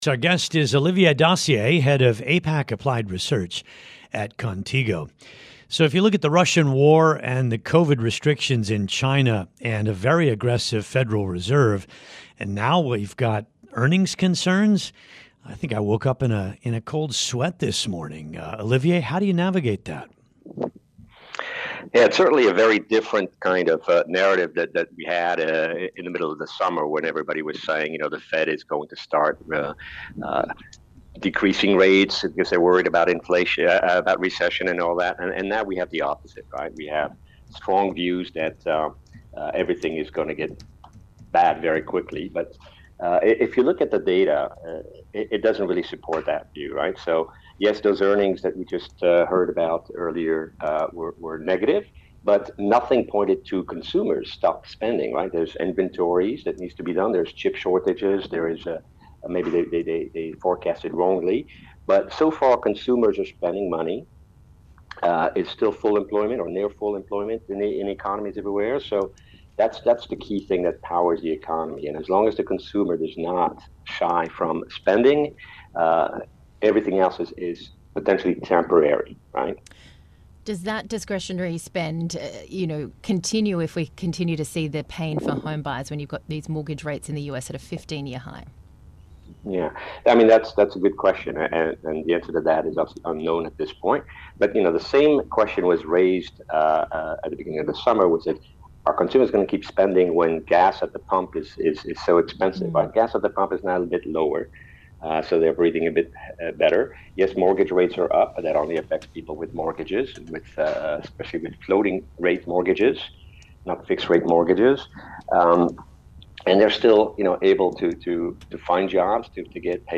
(Radio)